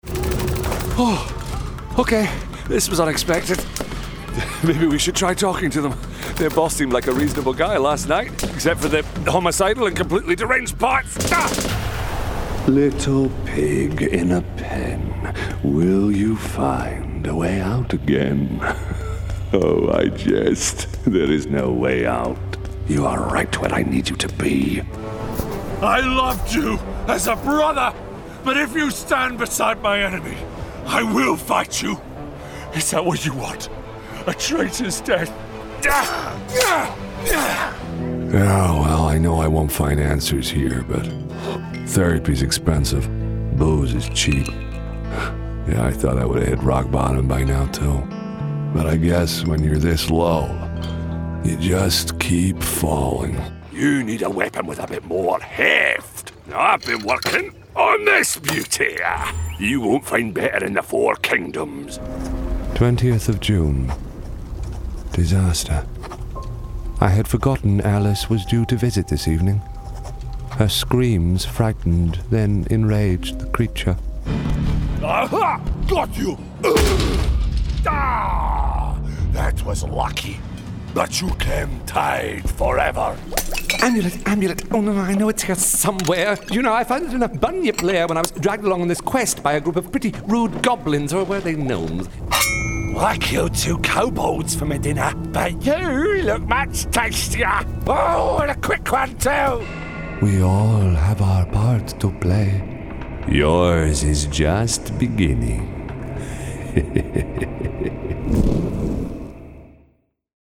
Video Game Showreel
He is proficient in UK and American accents, and his deep, resonant and clear voice has featured in over a hundred audiobooks to date.
Male
Gravelly